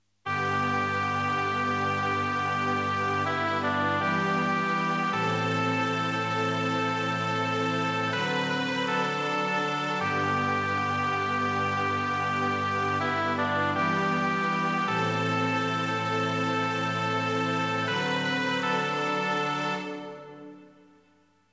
1-S